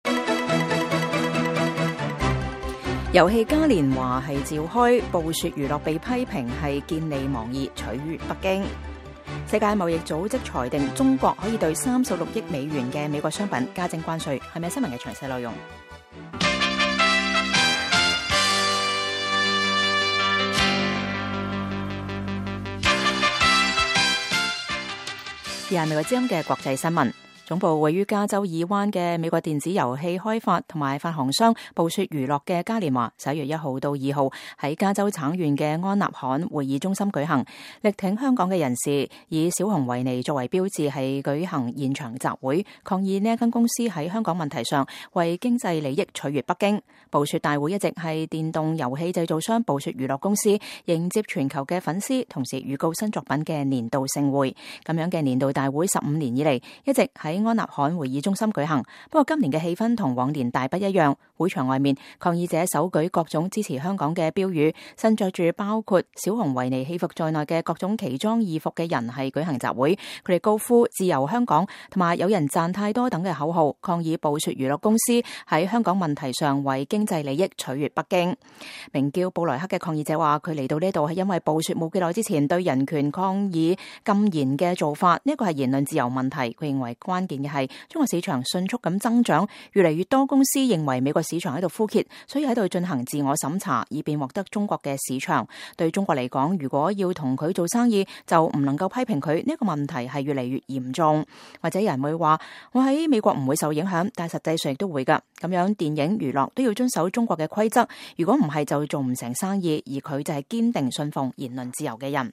會場外面，抗議者手舉各種支持香港的標語、身著包括維尼熊戲服在內的各種奇裝異服舉行集會。他們高呼“自由香港”和“有人賺太多”等口號，抗議暴雪娛樂公司在香港問題上為經濟利益取悅北京。